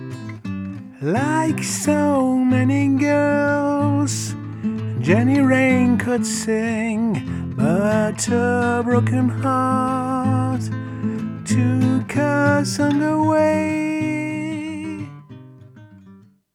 J'ai acheté un t bone sc 450 à coupler avec les akai j'ai fait quelques test vite fait, un court extrait du son et j'en profite ,j'essaye un nouveau plugin,
Le A est un peu plus fin, plus medium mais du coup un peu mieux défini.
A la première écoute je trouvais le A plus "intime" plus "doux"moins devant, moins "bright"